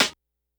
Perc_118.wav